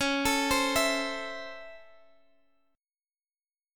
Listen to C#mM7#5 strummed